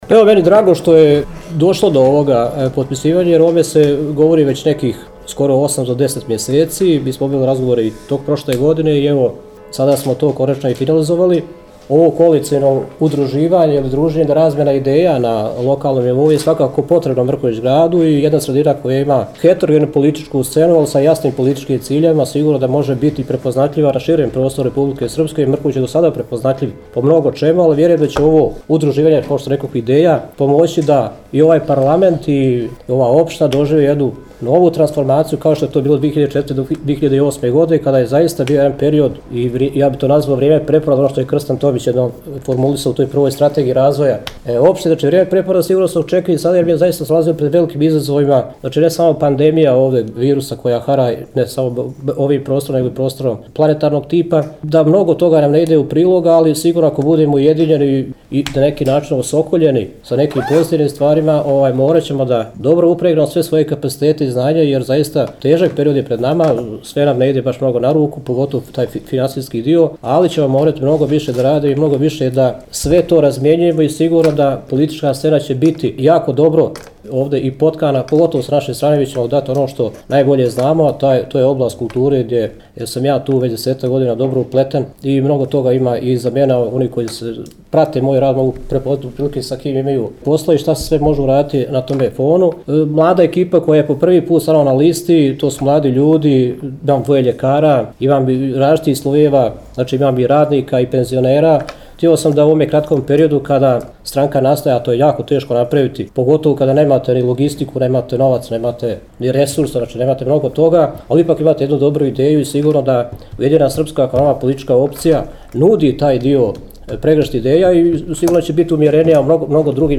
izjavu